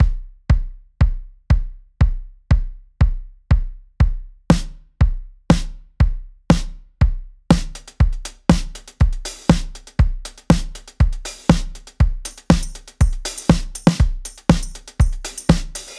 定番ビート – ４つ打ち
たとえばテクノやディスコ、EDMなどのジャンルでは、一定のタイミングでドシドシと低音が刻まれる4つ打ちFour-on-the-floorのビートが定番です。
心臓の鼓動のように一定で刻まれるビートには安定感があって、テクノやEDMをはじめとする電子音楽における定番中の定番です。